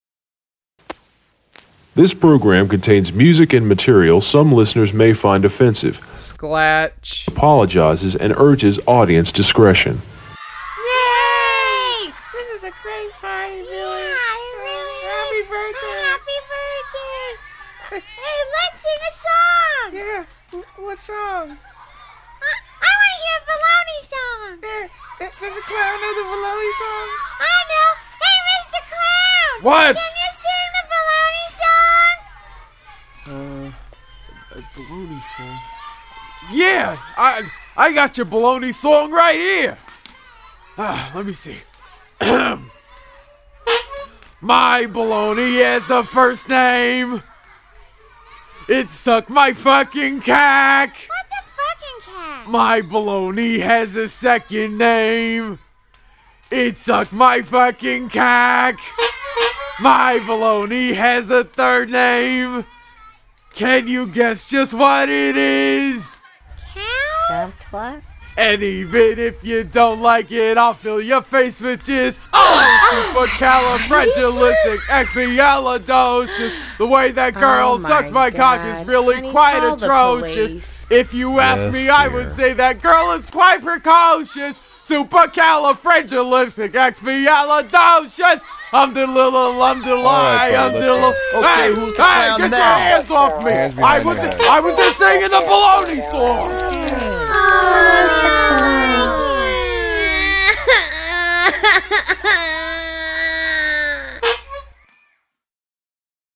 Check out the hidden bonus comedy track from